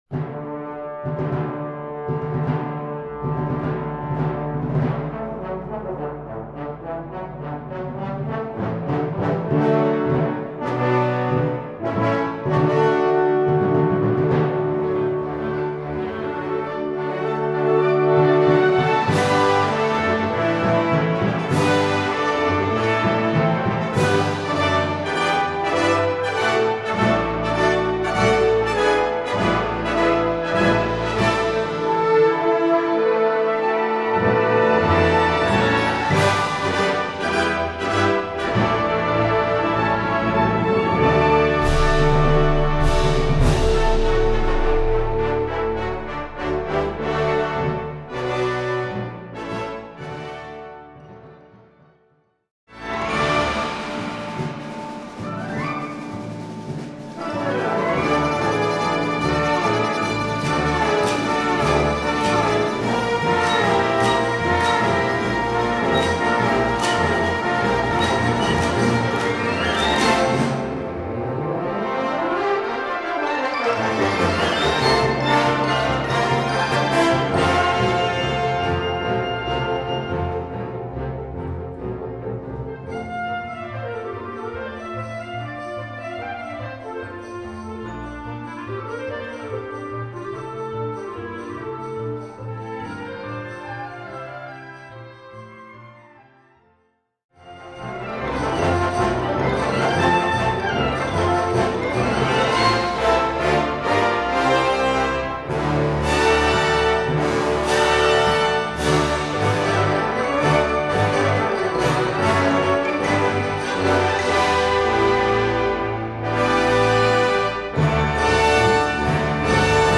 Categorie Harmonie/Fanfare/Brass-orkest
Subcategorie Ouverture (originele compositie)
Bezetting Ha (harmonieorkest)
is een feestelijke ouverture